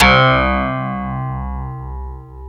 Index of /90_sSampleCDs/Club-50 - Foundations Roland/PNO_xFM Rhodes/PNO_xFM Rds C x2